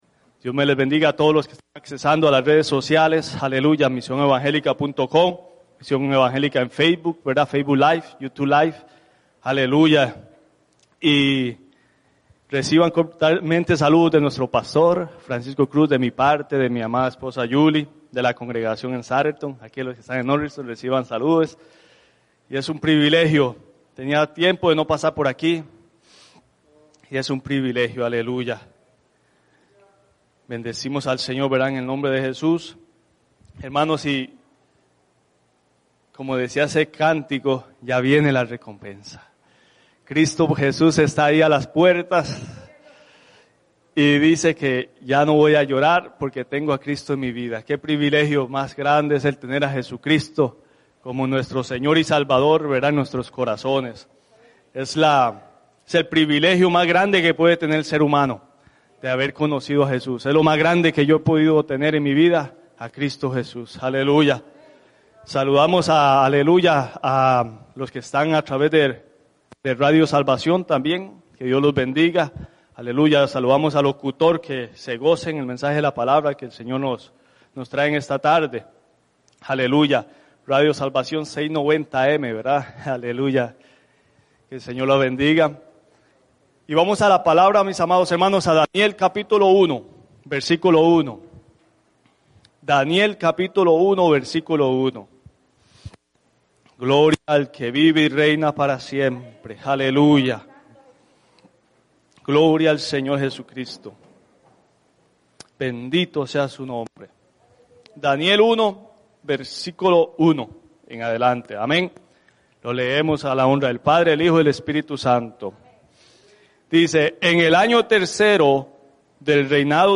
en la Iglesia Misión Evangélica en Norristown, PA